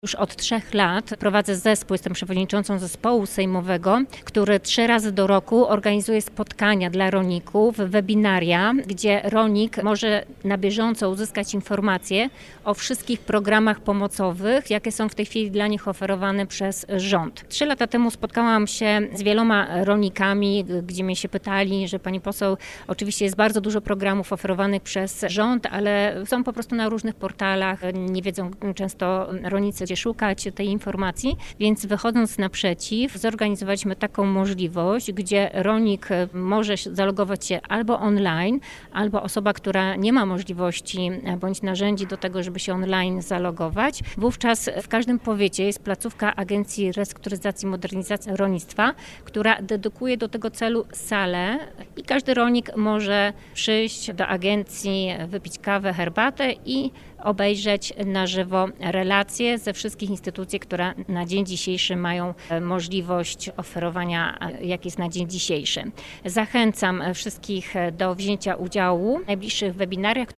-Ogromnie się cieszę, że nasza wspólna praca przynosi zamierzone efekty. Płyną do mnie pozytywne sygnały, po tych spotkaniach, mówi poseł na Sejm Agnieszka Soin, która zaprasza na webinarium.